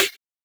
Drum_Hits
Snare03.wav